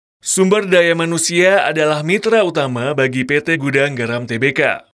Indonesian Voice Over Talent
Sprechprobe: Sonstiges (Muttersprache):